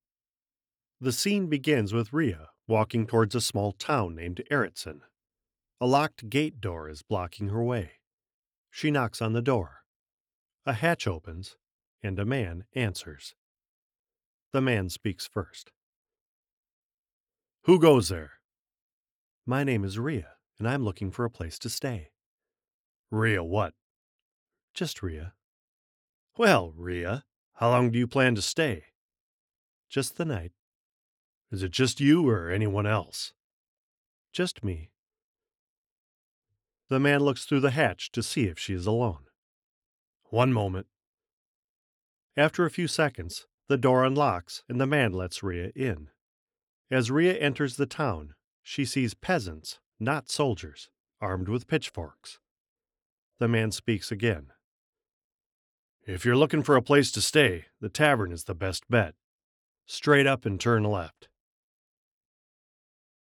Videogame Audition
North American (General); North American (Midwest)
Middle Aged
Cosmic Dawn_Man_Audition Reel.mp3